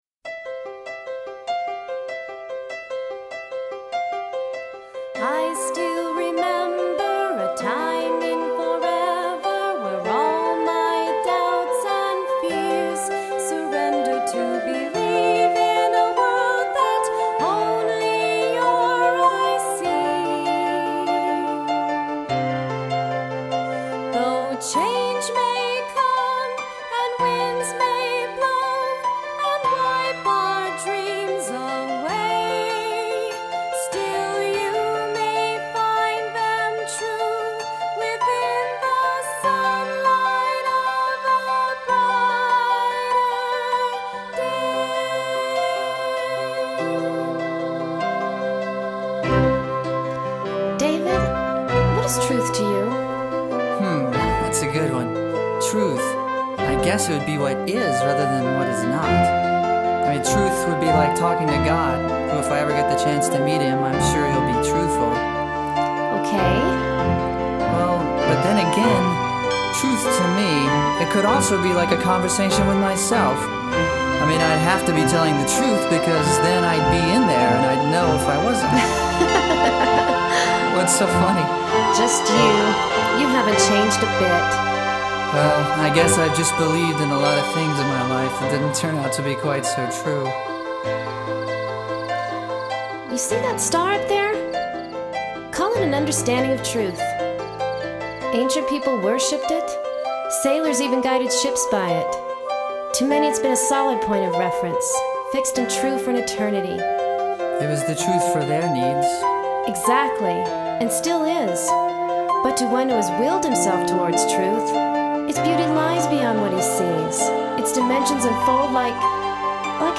Keyboards